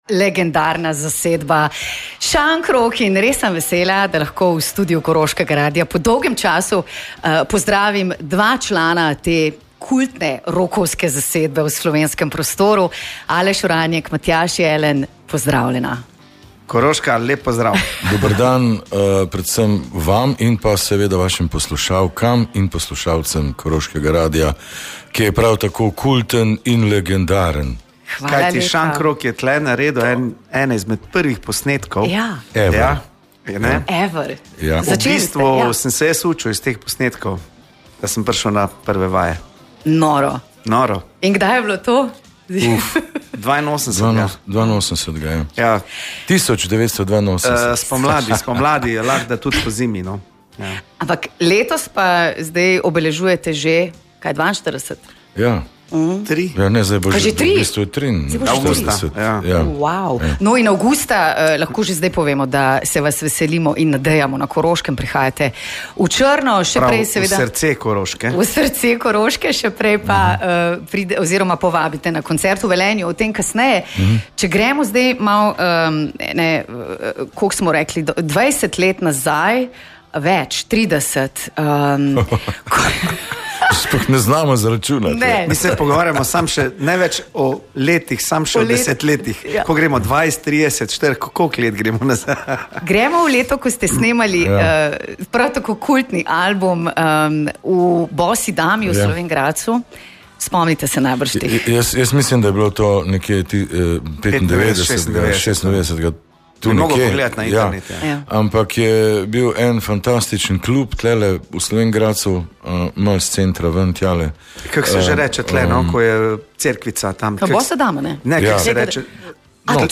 Velenjski rockerji so se ustavili v našem studiu in izdali skrivnosti novega albuma ter načrte za prihodnost.